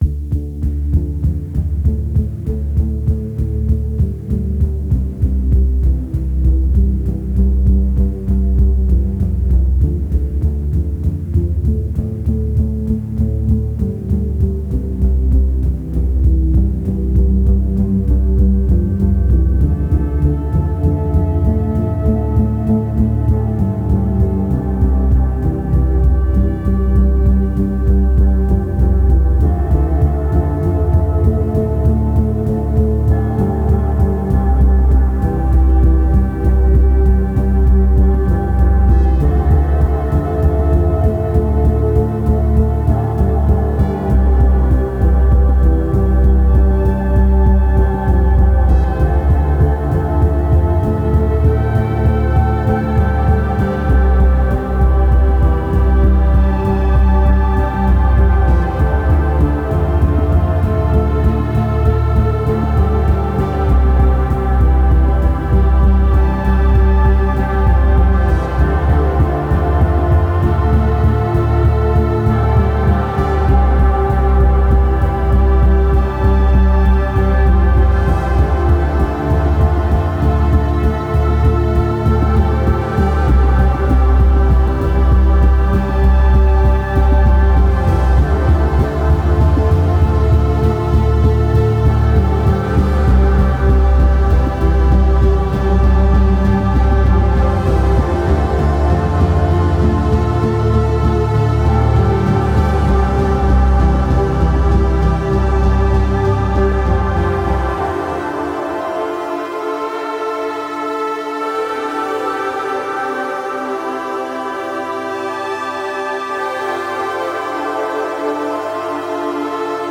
Pulsating synth arpeggios and hazy textures.